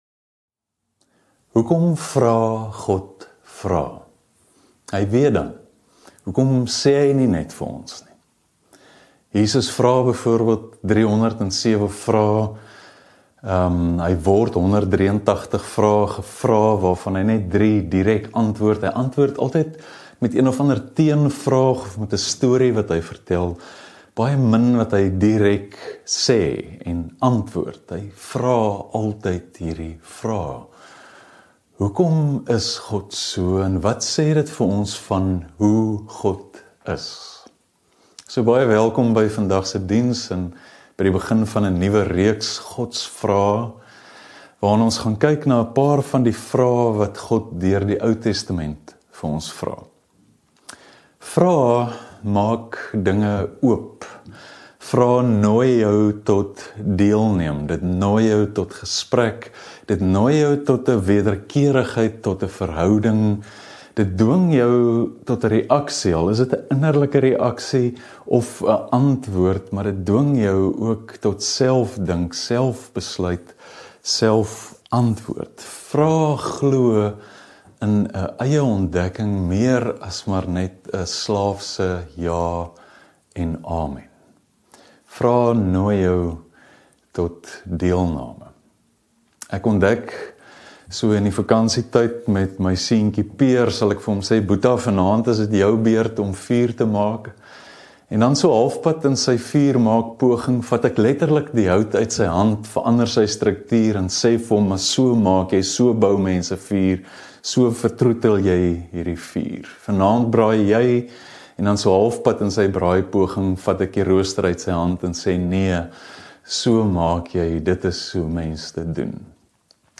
Stellenbosch Gemeente Preke 25 Julie 2021 || Godsvrae - Waar is jy?